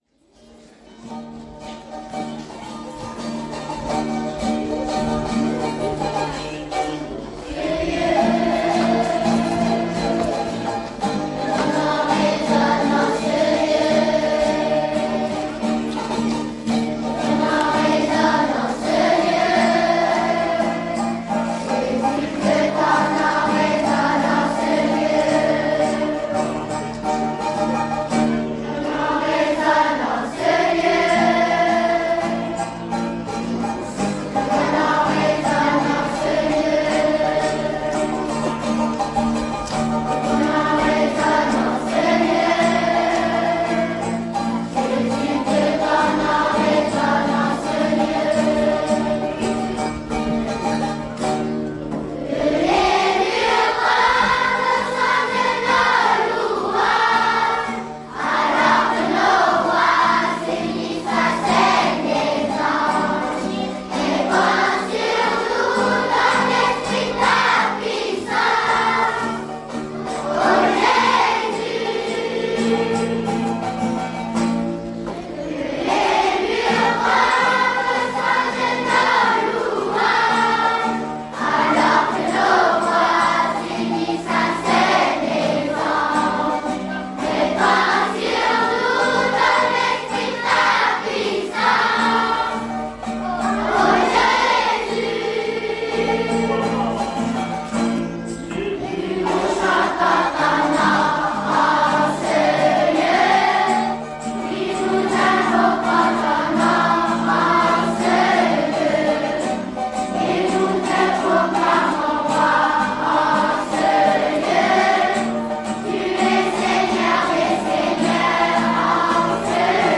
A 8h30 ce vendredi 06 octobre, nous avons fait notre célébration de fin de période.
Nous vous offrons le chant d’entrée, avec les paroles!